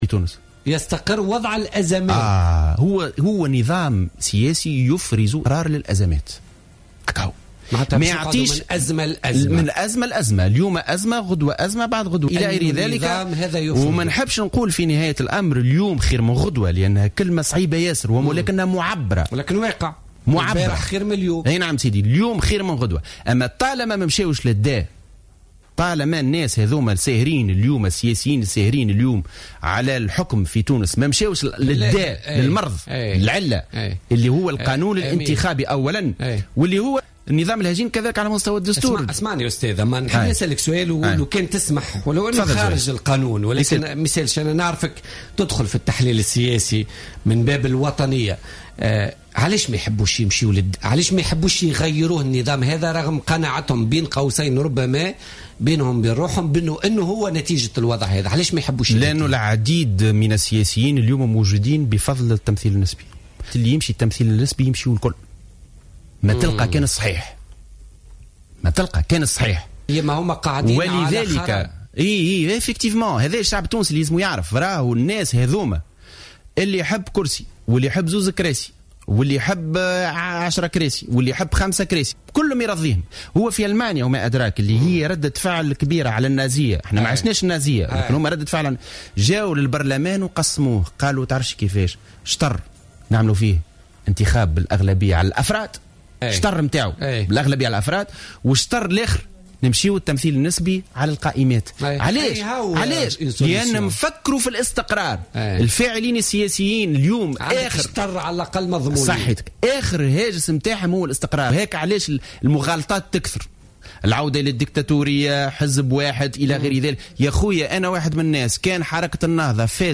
وشدد على "الجوهرة أف أم" في برنامج "بوليتيكا" بضرورة مراجعة نظام الاقتراع بالقانون الانتخابي، موضحا أن التمثيل النسبي يؤدي إلى صعود أحزاب بلا برامج وأشخاص يريدون الظهور بالحكم فقط، بحسب ترجيحه، مؤكدا أيضا على ضرورة مراجعة بنود الدستور المتعلقة بصلاحيات رئيس الحكومة ورئيس الدولة التي أدت إلى حكم برأسين. وأضاف أن العديد من السياسيين يفضلون التّمثيل النسبي لأن نظام الحزب الأغلبية المطلقة سيقلّص من فرص فوزهم بالانتخابات، وهو واقع أدى إلى "استقرار الأزمات" السياسية في تونس.